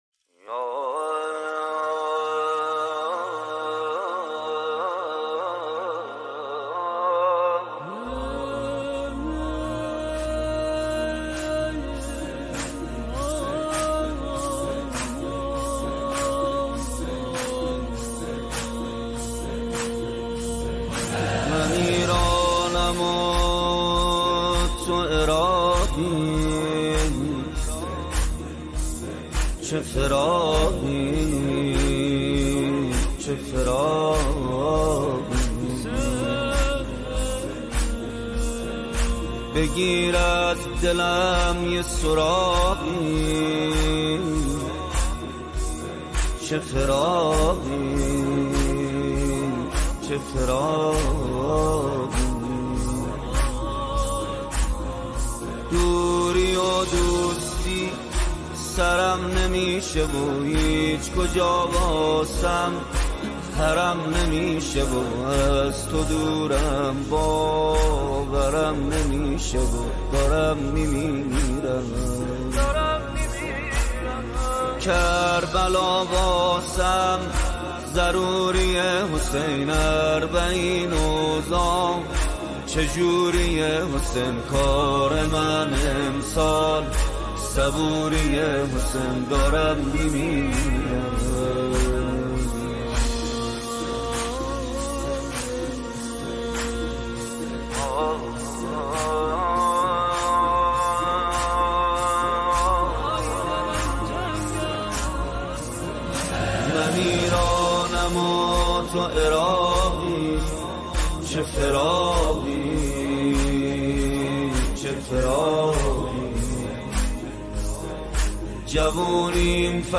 این استودیوییش هست